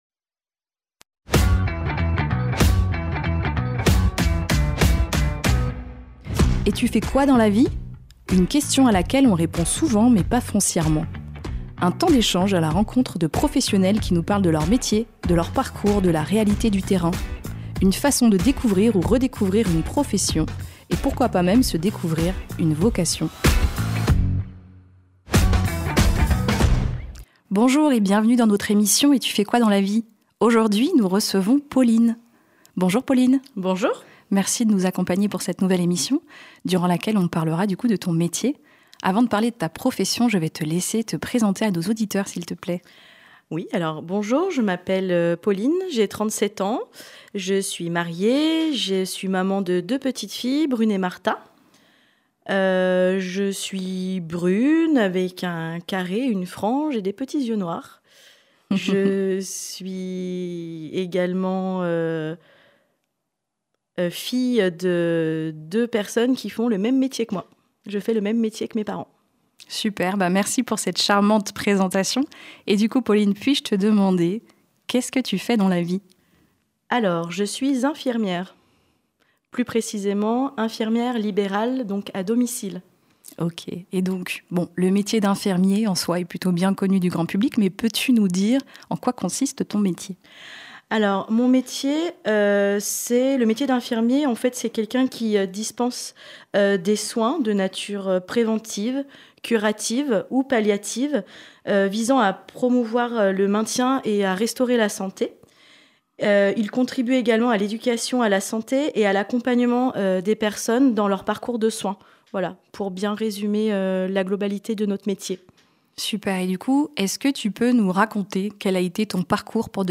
Découvrez des conversations sincères avec des professionnels authentiques qui sauront, qui sait, éveiller chez vous, une ou des vocations.